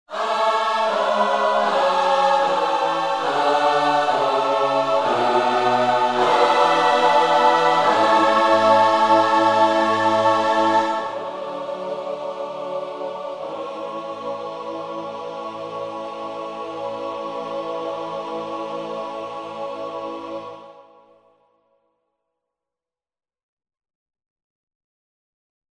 Gospel Ohs demo =1-D01.mp3